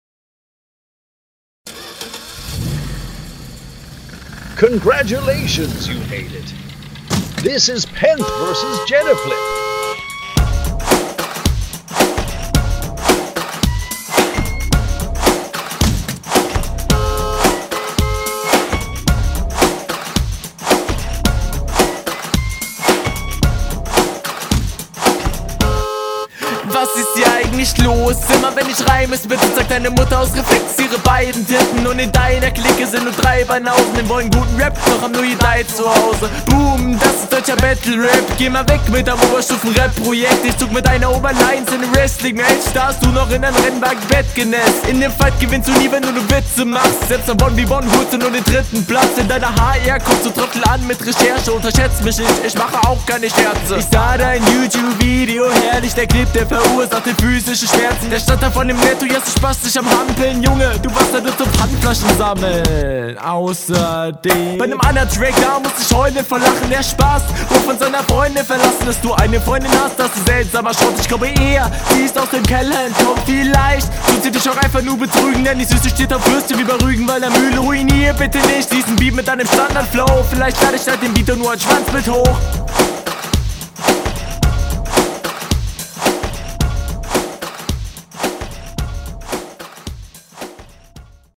Autohupenbeat :O Reime gut.
Flow routiniert.
warum aber so langes Intro?